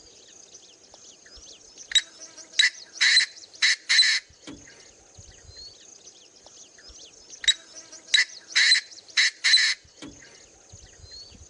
中华鹧鸪鸣叫声